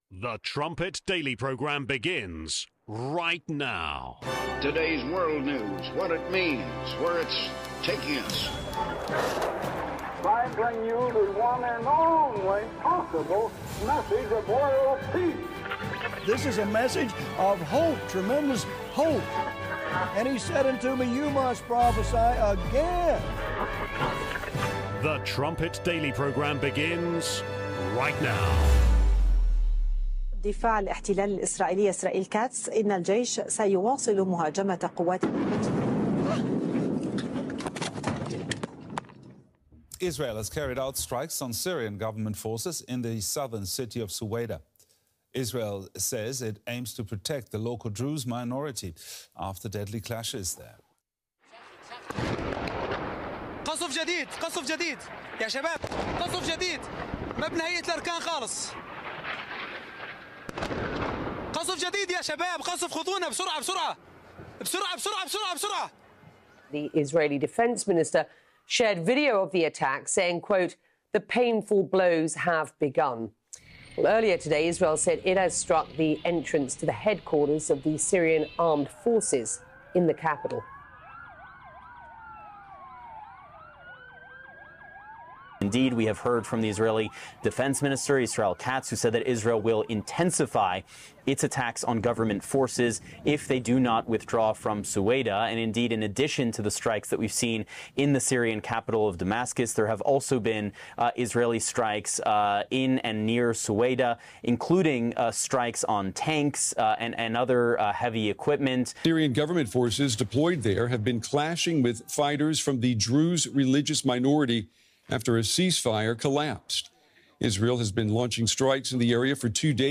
29:20 Interview With Melanie Phillips, Part 2 (26 minutes)